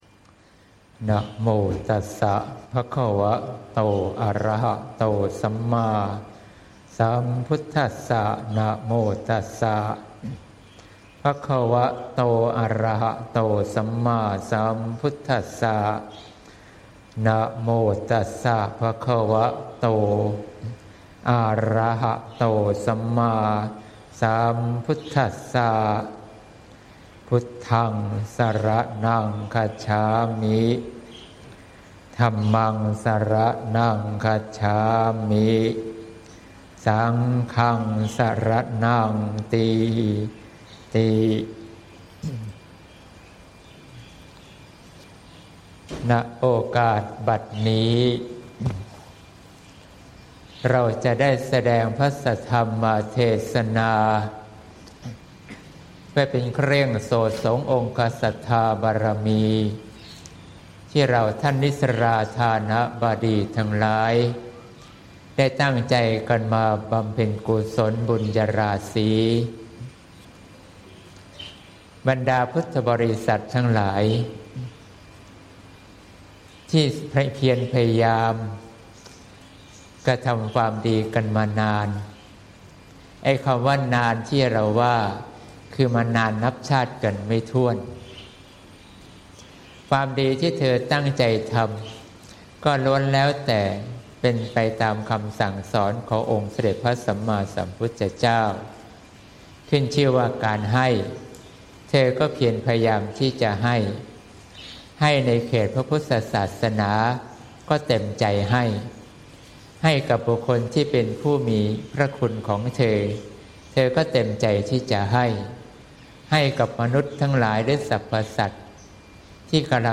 เทศน์ (เสียงธรรม ๙ ส.ค. ๖๘)